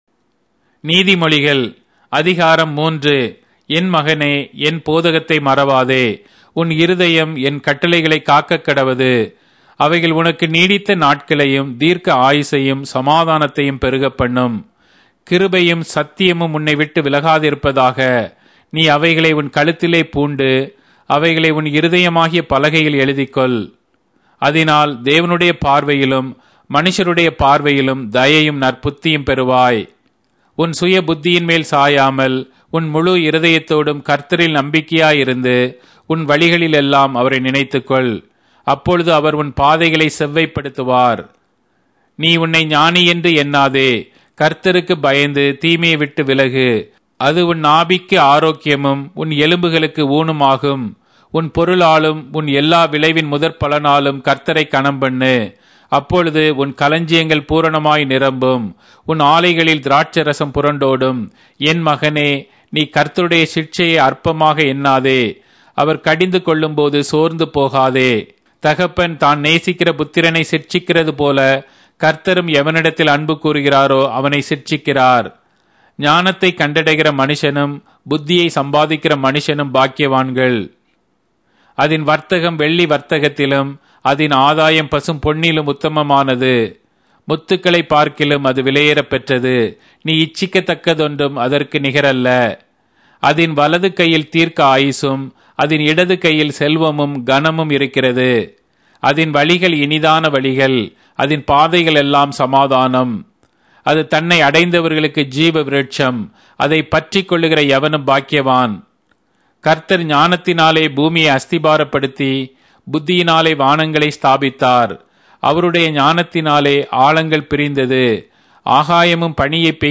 Tamil Audio Bible - Proverbs 20 in Mkjv bible version